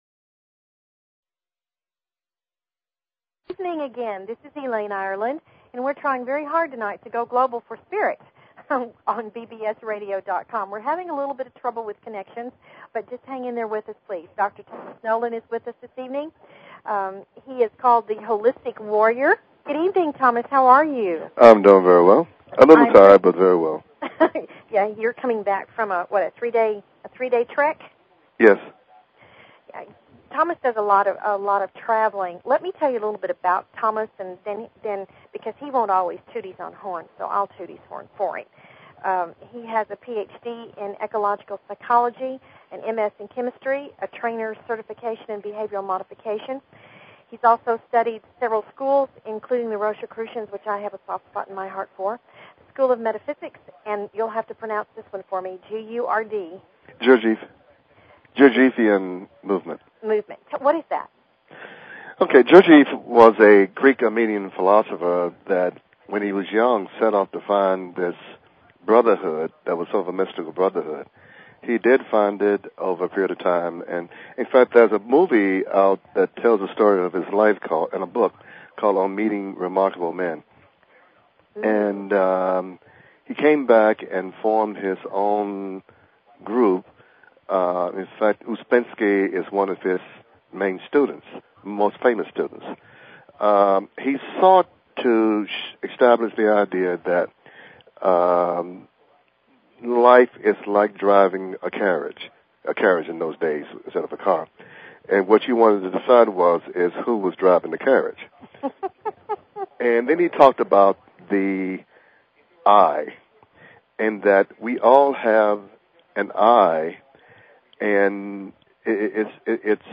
Talk Show Episode, Audio Podcast, Going_Global_for_Spirit and Courtesy of BBS Radio on , show guests , about , categorized as
They invite you to call in with your questions and comments about everything metaphysical and spiritual!"